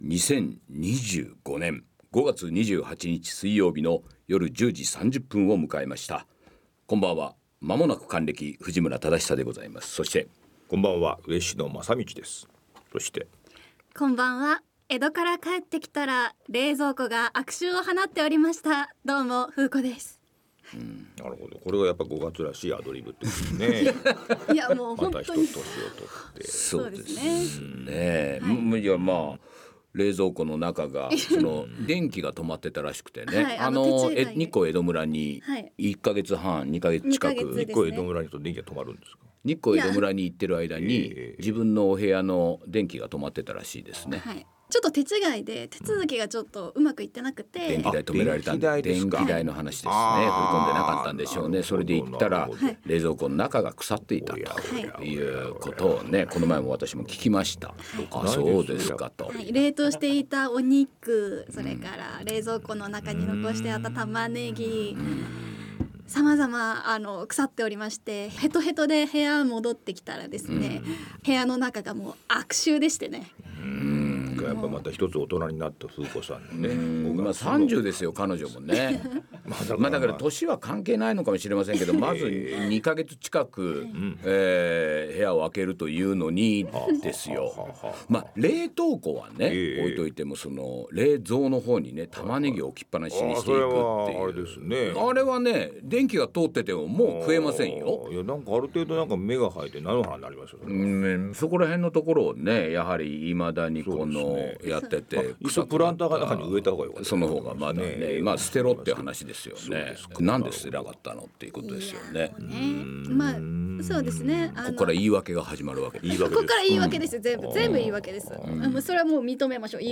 この番組では日々全国を飛び回る活動の報告や、これからの彼の活動が語られる、筋書きのないラジオである。